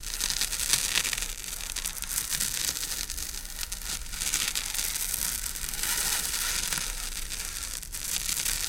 Fuse Burn On Wood, Crackley, Loop